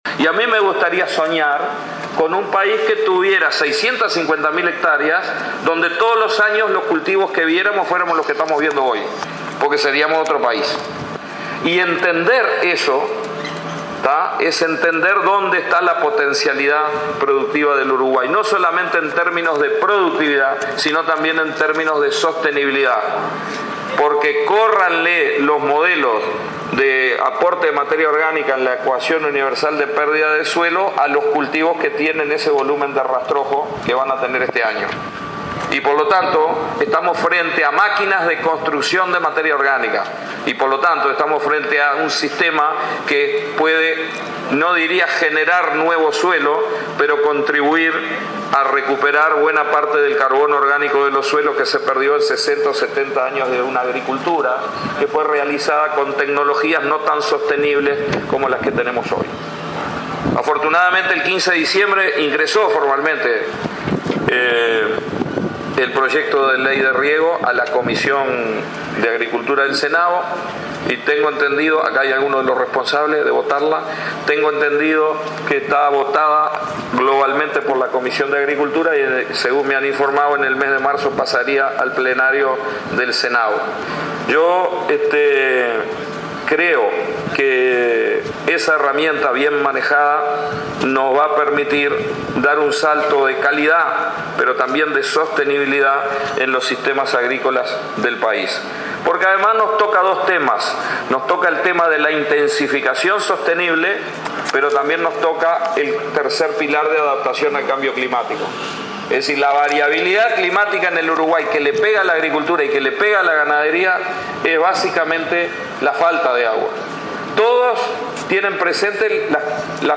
El ministro de Ganadería, Tabaré Aguerre, destacó este miércoles, durante la inauguración de la Expoactiva de Soriano, la importancia de la ley de riego a discusión en el Parlamento.